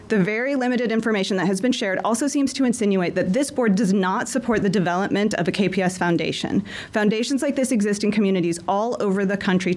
A parent asked trustees last Thursday night if the board opposed the foundation.